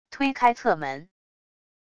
推开侧门wav音频